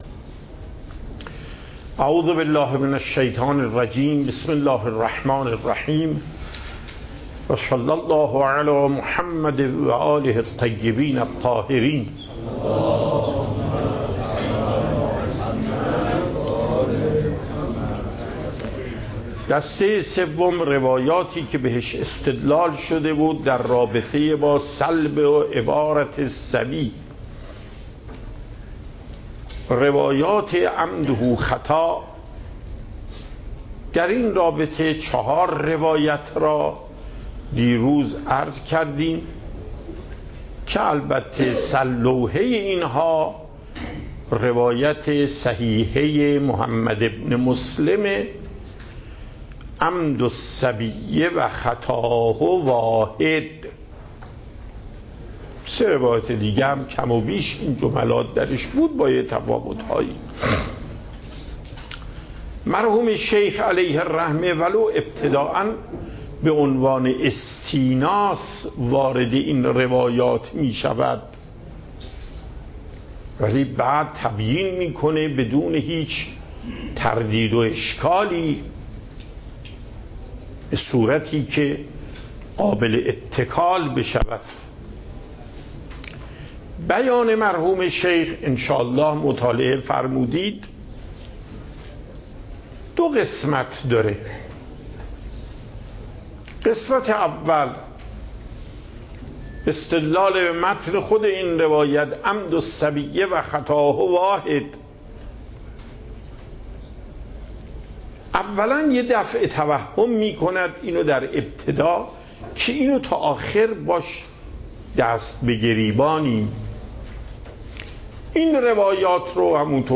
درس فقه آیت الله محقق داماد